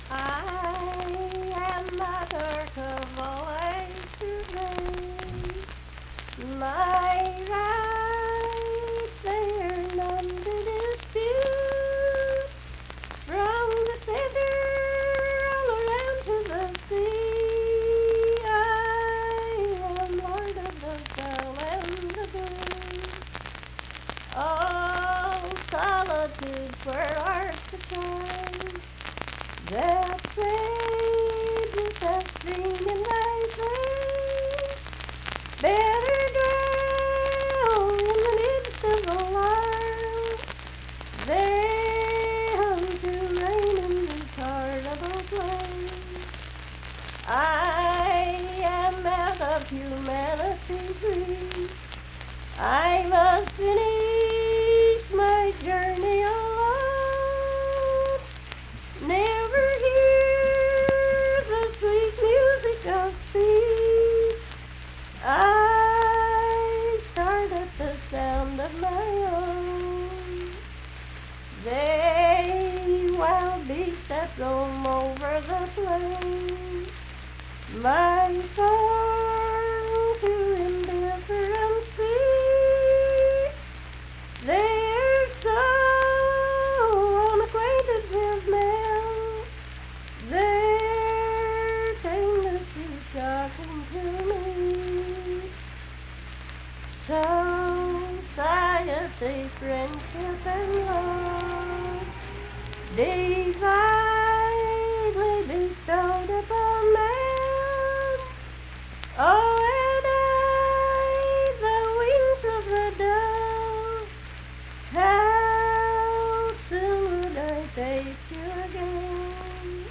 Unaccompanied vocal music performance
Miscellaneous--Musical
Voice (sung)
Braxton County (W. Va.)